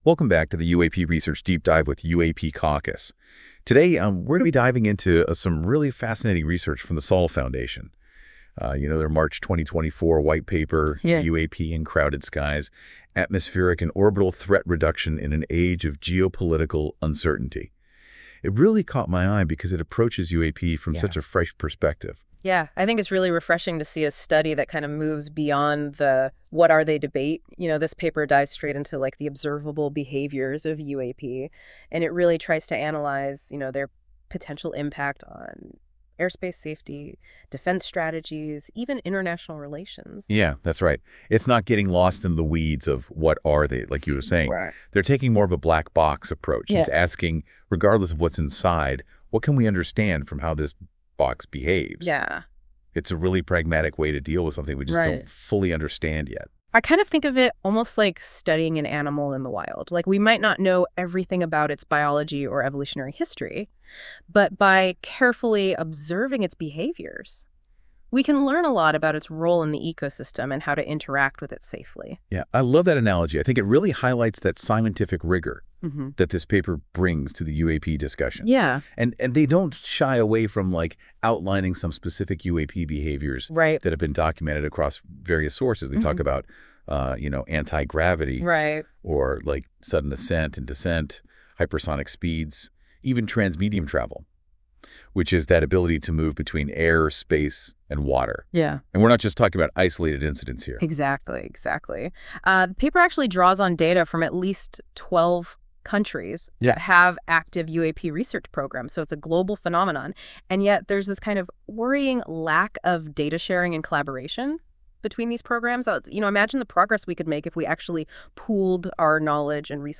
Powered by NotebookLM. This AI-generated audio may not fully capture the research's complexity.